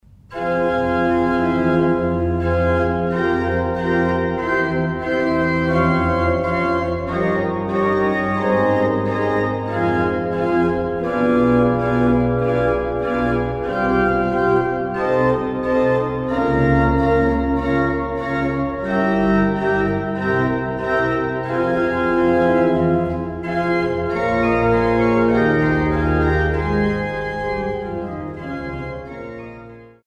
an der Renkewitz-Orgel auf Schloss Augustusburg